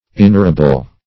inarable - definition of inarable - synonyms, pronunciation, spelling from Free Dictionary Search Result for " inarable" : The Collaborative International Dictionary of English v.0.48: Inarable \In*ar"a*ble\, a. Not arable.